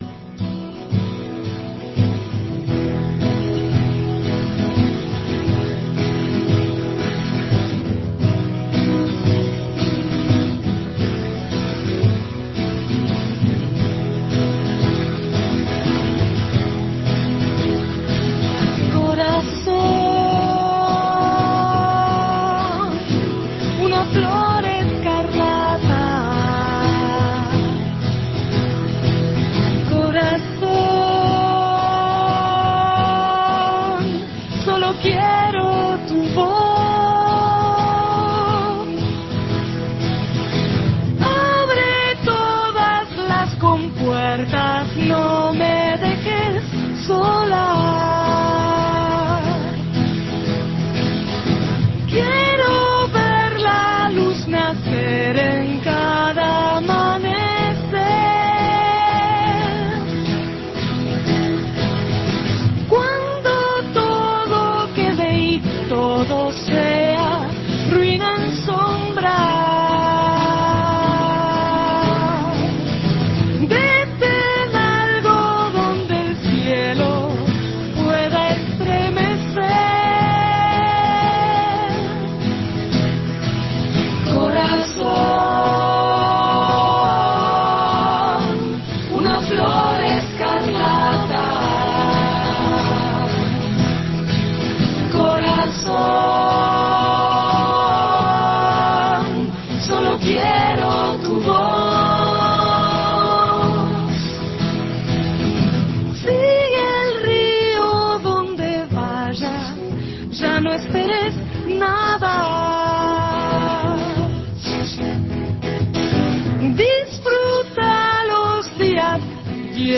visitaron Otra Historia, cantaron, presentaron su disco e invitaron a su show en el Espacio Guambia.